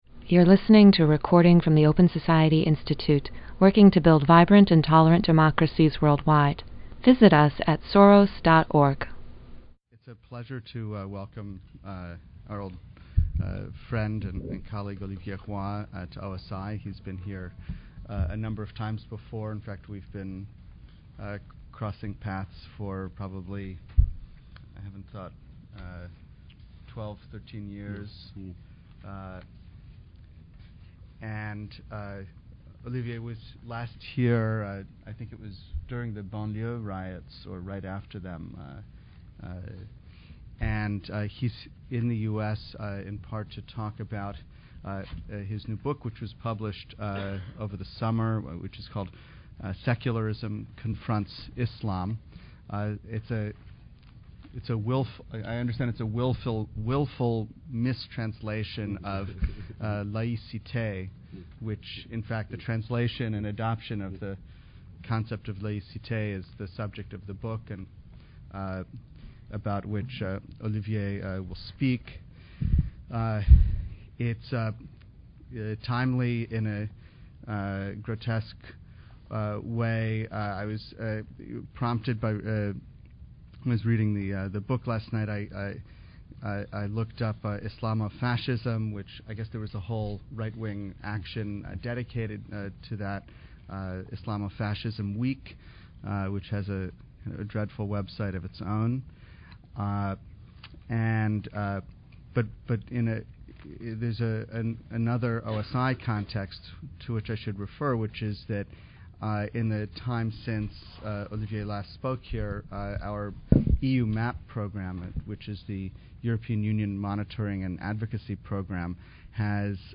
OSI’s Middle East & North Africa Initiative presented a talk with Olivier Roy, author of Secularism Confronts Islam.